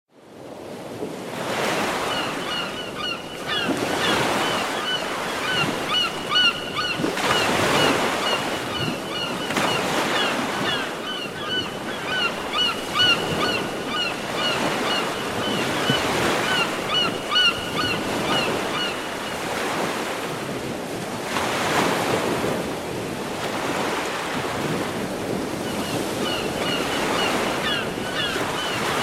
bereg-moria_24651.mp3